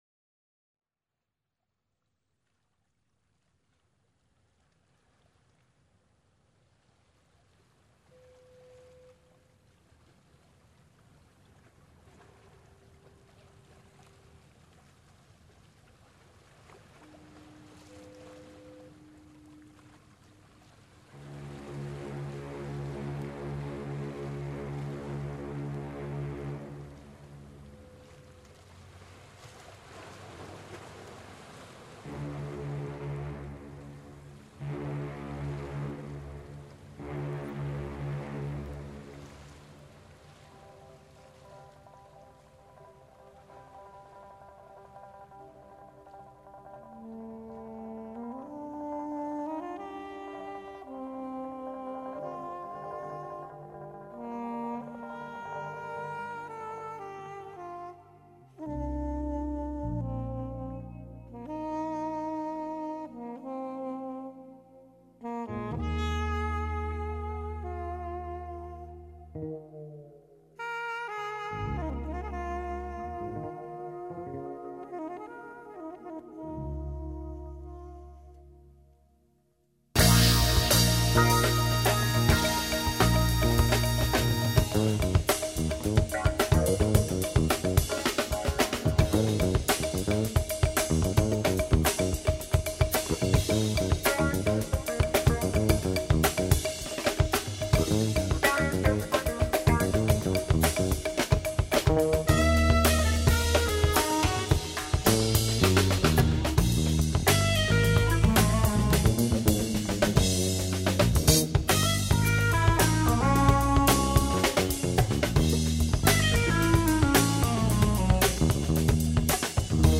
Jazzstückes
Jazzrock